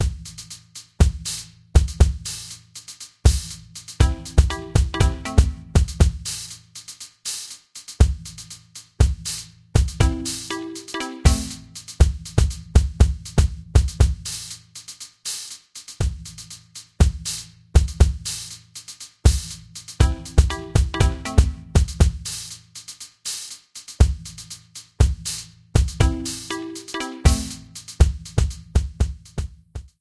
Key G Mixolydian